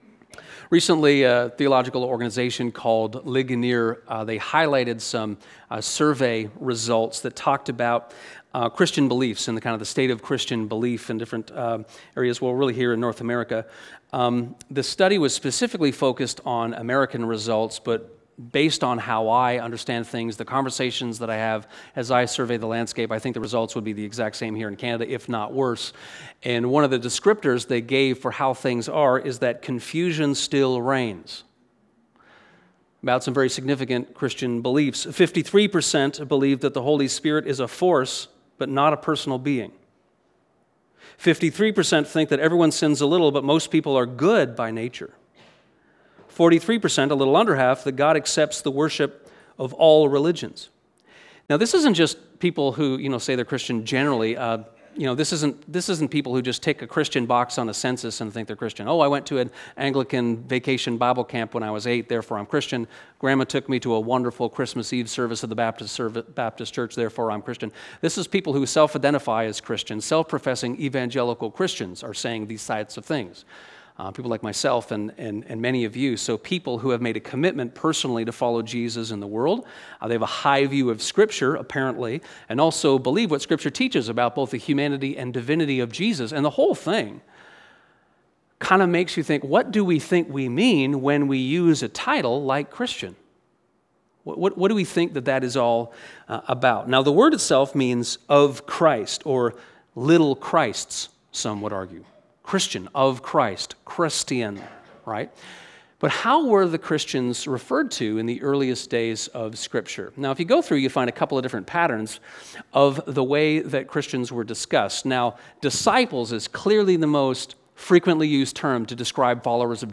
Sermons | Westminster
This sermon on 1 John 3:11-24 unpacks the text, line by line, and invites us to reconsider the critical idea of love flowing from faith.